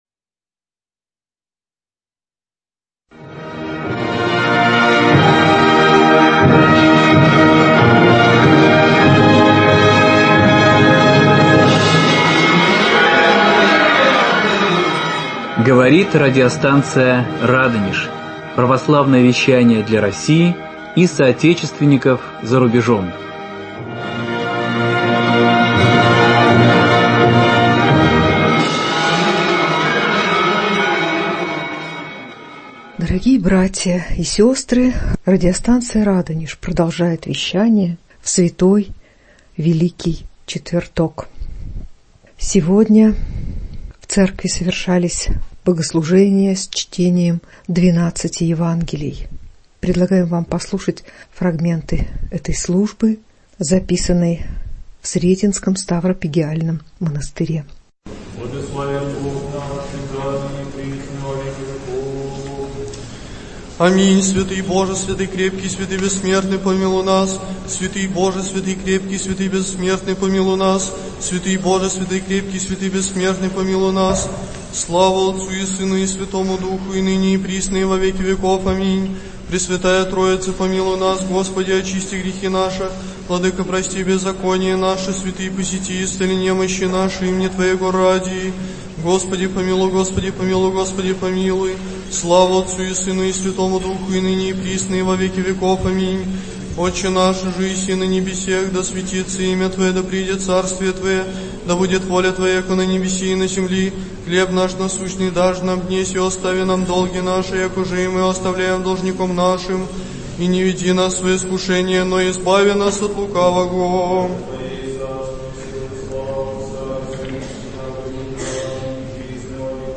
Фрагменты утрени Великой пятницы с чтением 12 Евангелий в Сретенском монастыре ч.1